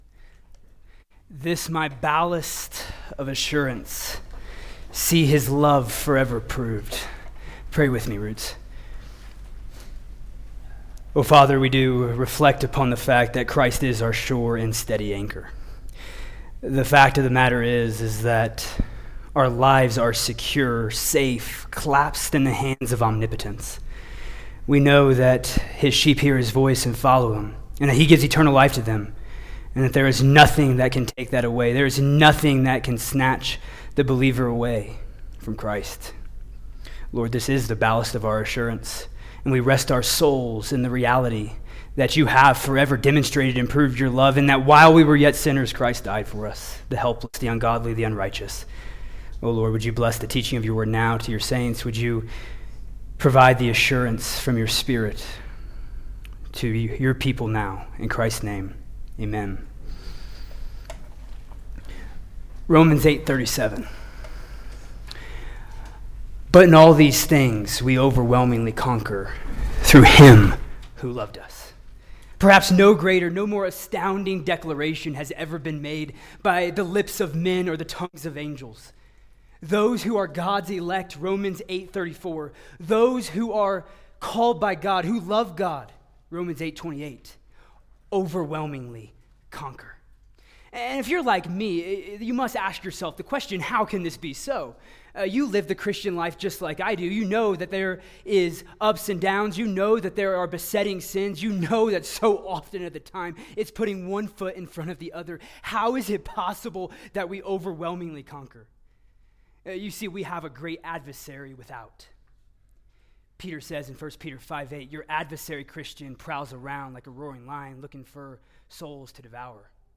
College/Roots Roots Winter Retreat - 2025 Audio ◀ Prev Series List Next ▶ Previous 1.